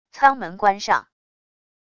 舱门关上wav音频